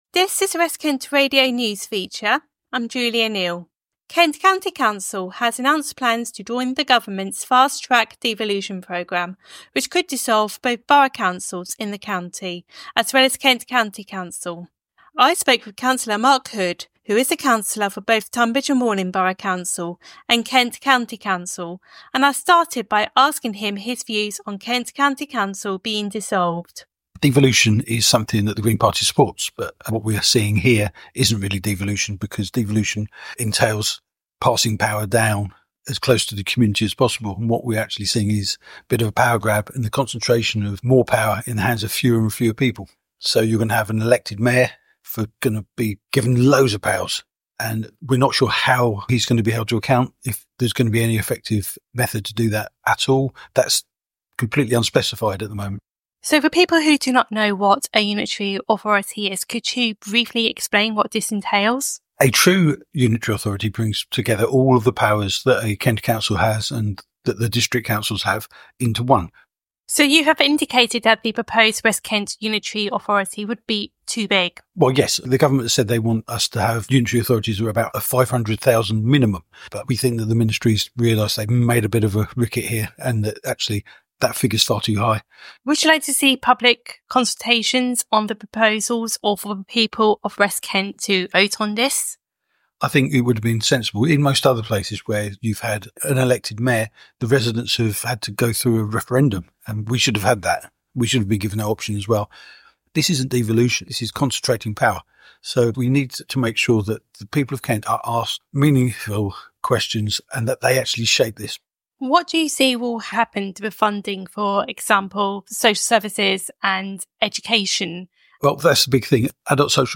You can hear more of Mark Hood's views in this audio: